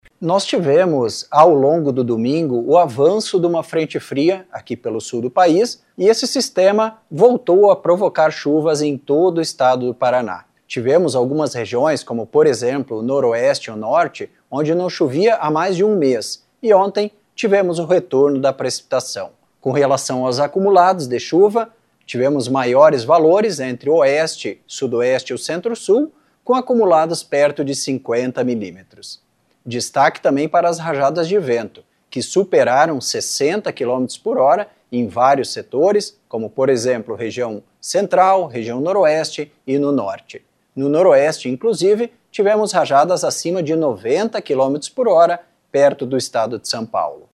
O meteorologista